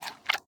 mob / panda / eat8.ogg
eat8.ogg